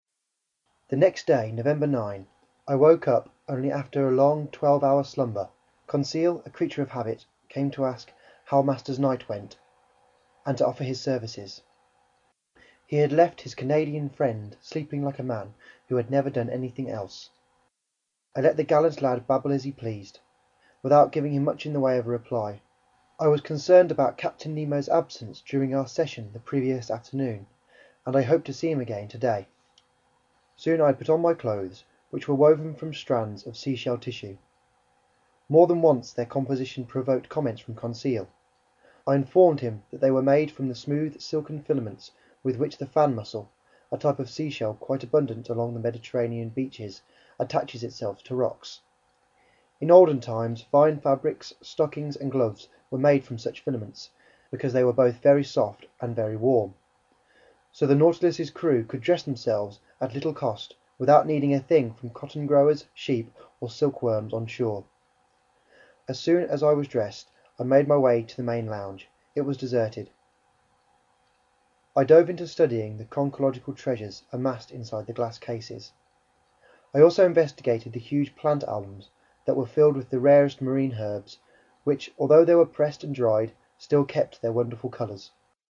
在线英语听力室英语听书《海底两万里》第199期 第15章 一封邀请信(1)的听力文件下载,《海底两万里》中英双语有声读物附MP3下载